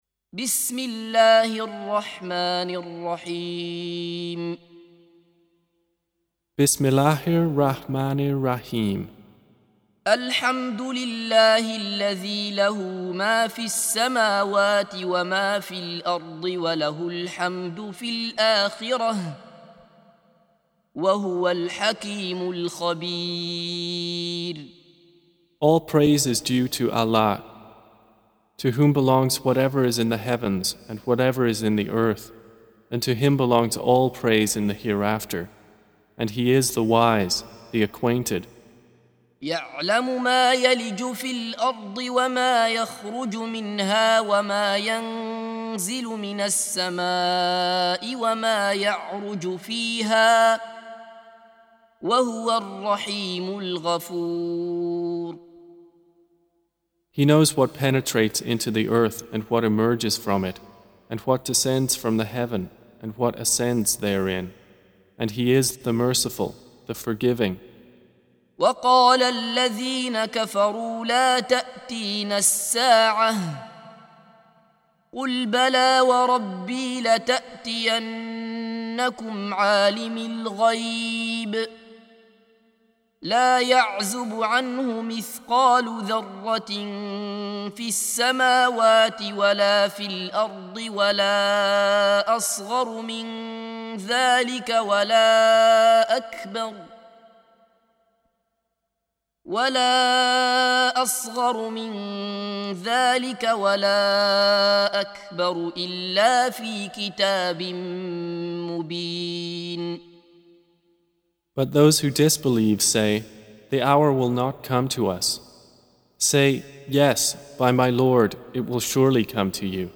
Surah Repeating تكرار السورة Download Surah حمّل السورة Reciting Mutarjamah Translation Audio for 34.